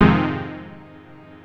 HOUSE 8-R.wav